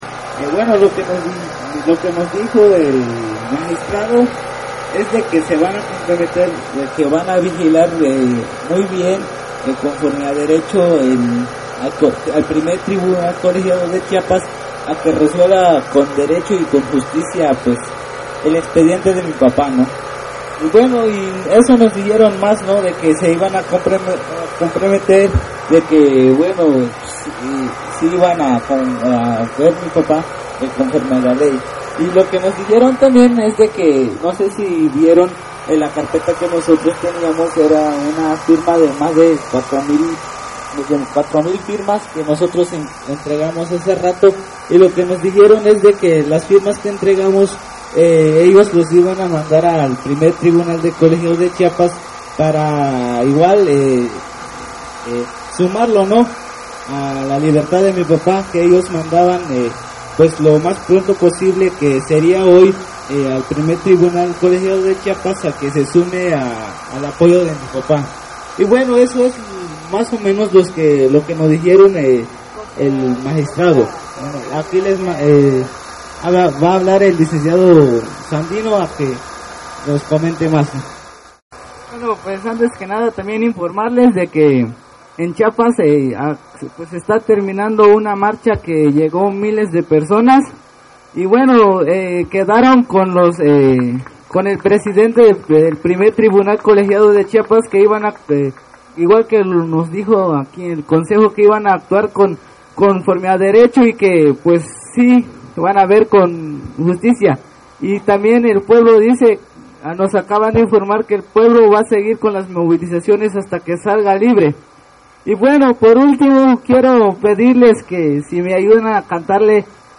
Desde muy temprana hora se congregaron aproximadamente 300 personas entre ellas compañerxs de la Sexta, organizaciones como la Coordinadora Nacional Plan de Ayala, medios libres, colectivos libertarios, entre otros.
Al término del mitin se entonaron las mañanitas por parte de los asistentes